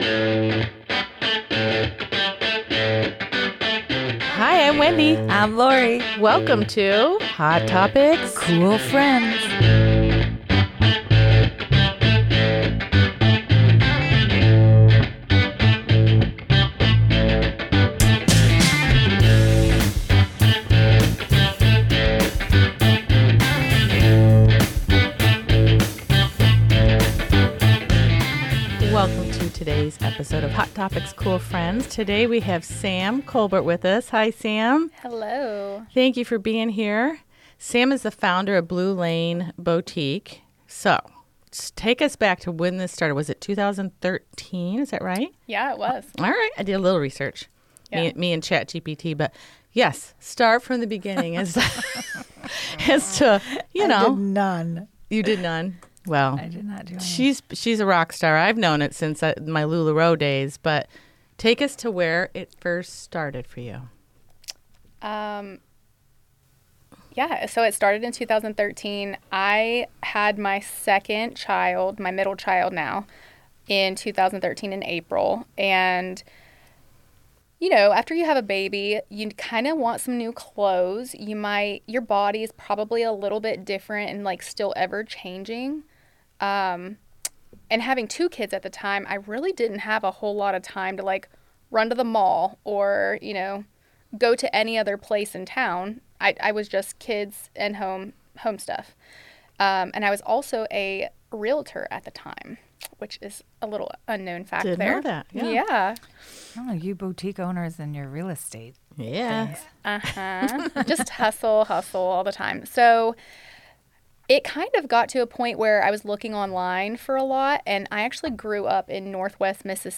Honest, inspiring, and full of laughs—this is one conversation you don’t want to miss.